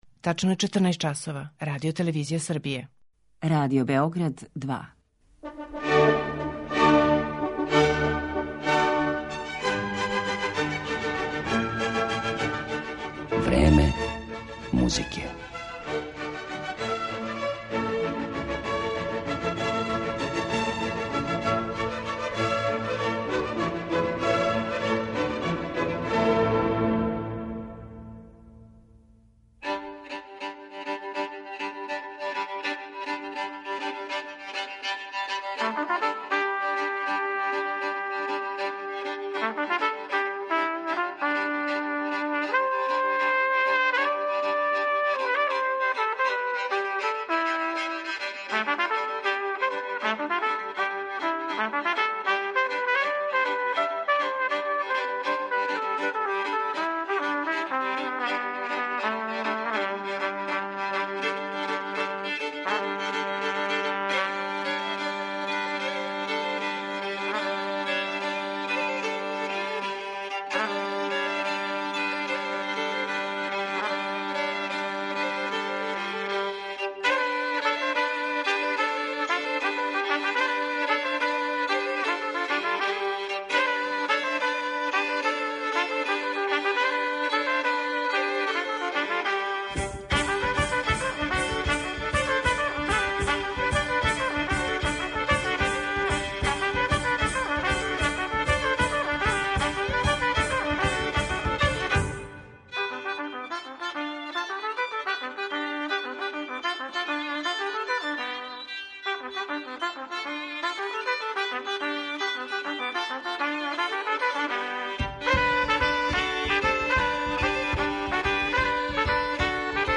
Неочекиване тонске боје дувачких инструмената